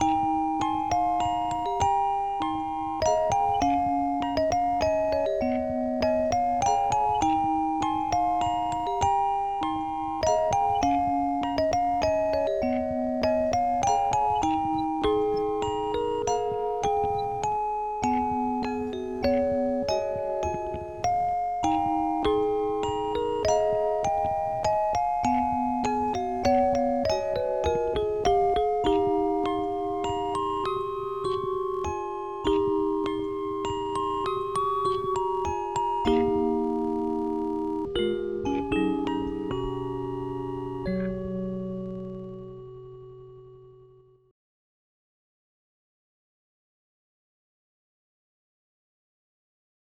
xm (FastTracker 2 v1.04)
Musicbox.wav